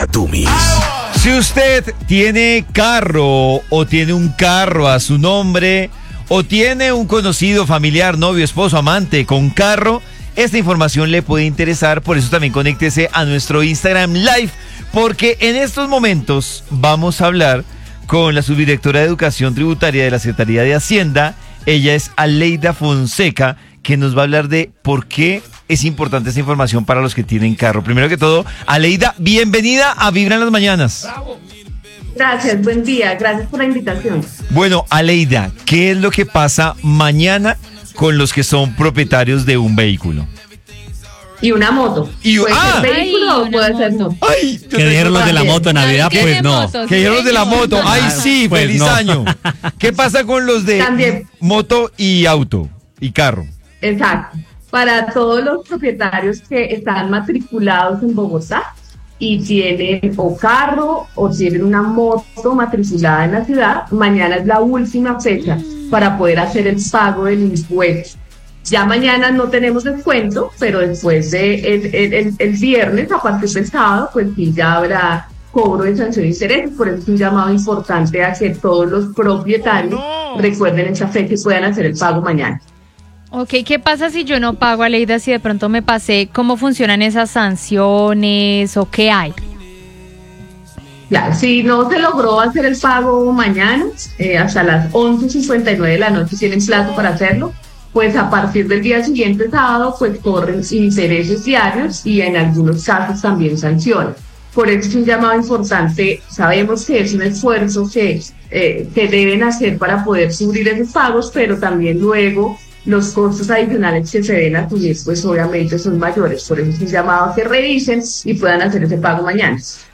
Imagen de emisora vibra Bogotá